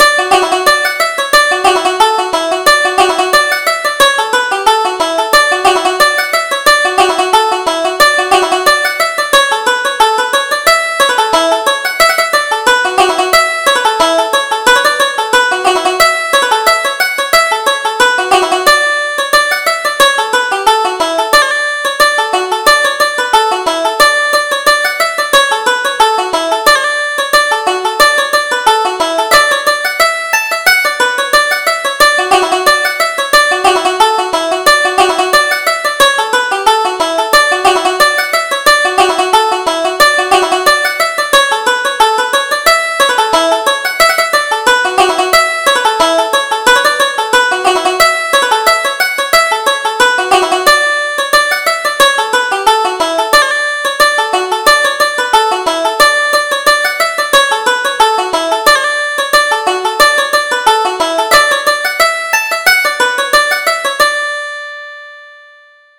Reel: The Dublin Reel